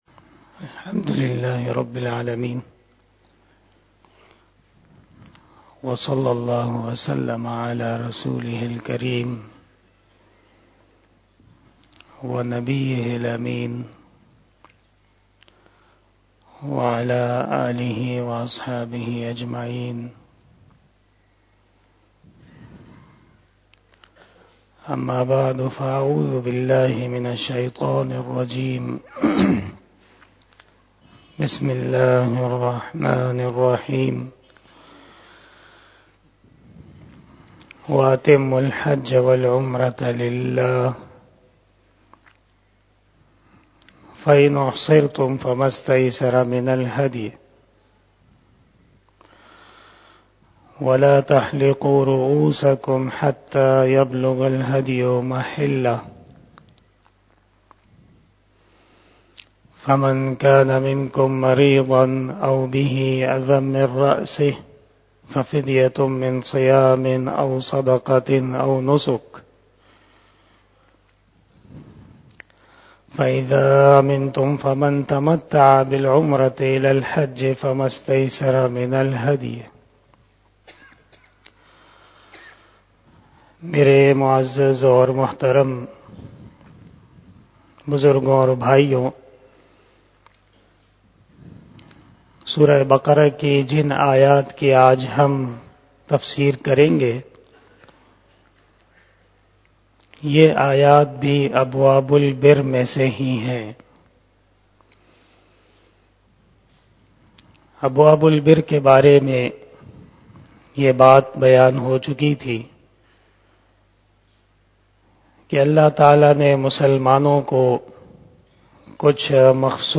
بیان شب جمعۃ المبارک05 جنوری2022 بمطابق 12 جمادی الثانی 1444ھ